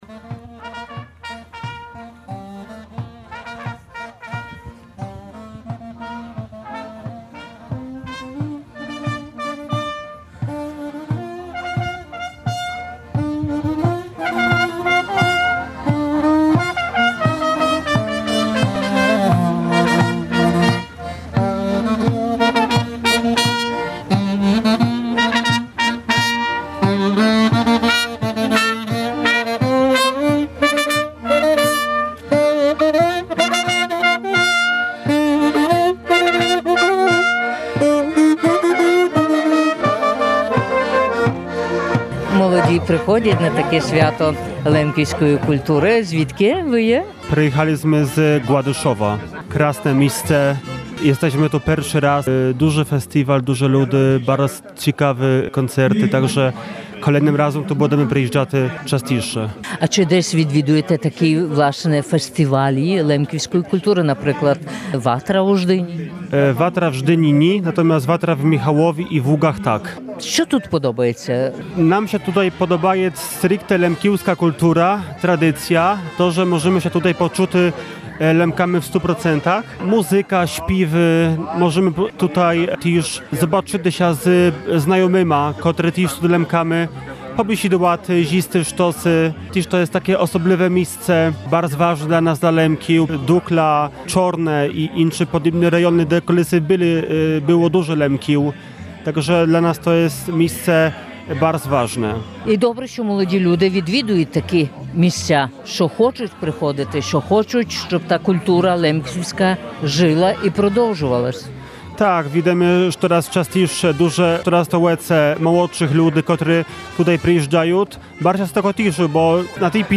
Два дні в Зидранові звучала лемківська пісня і слово. До села на свято Від Русаль до Яна приїхали лемки на щорічне свято.